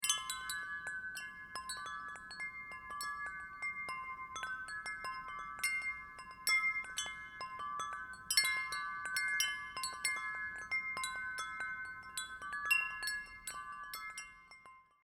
Patina Breeze Bronze Tuned Chime 32″
This collection is perfectly tuned to a delightful major hexatonic scale, or six-tone, scale that represents the sound of nature.
Size: 6 tubes- 5/8″ diameter